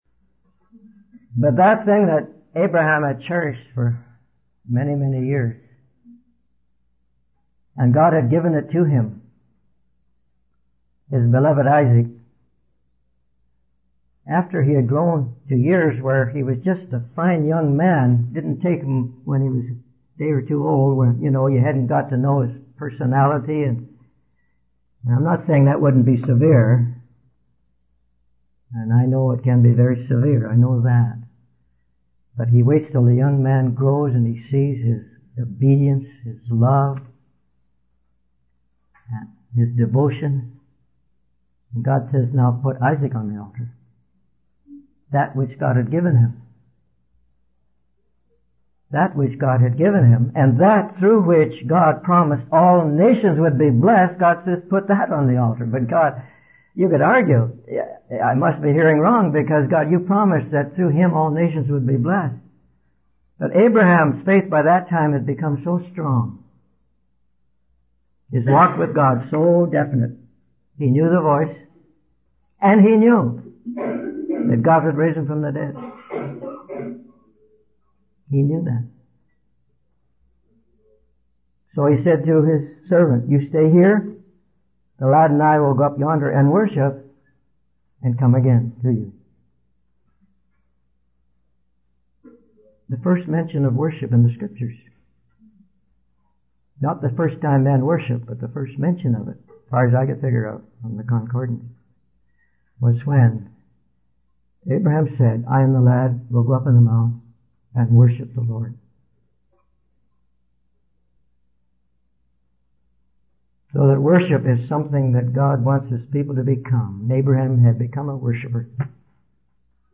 In this sermon, the preacher emphasizes the importance of being a priest unto the Lord Jesus. He starts by thanking God and asking for His help and guidance. The preacher then discusses the story of Gideon from the book of Judges, where God tells Gideon that he has too many people in his army and needs to reduce the number.